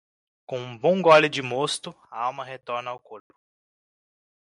Pronounced as (IPA) /ˈmos.tu/